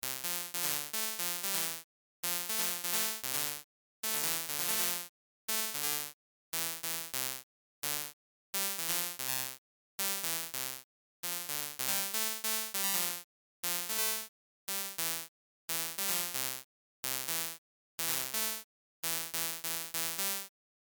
以下の例では、起動してから1秒後に発音がスタートする。